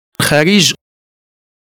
el_khalij_prononciation.mp3